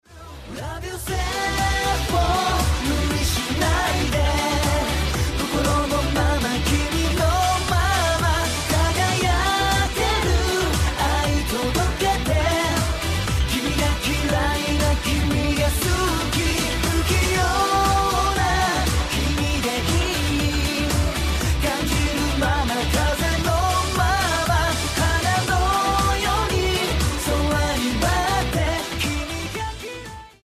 Бойз бенд из Японии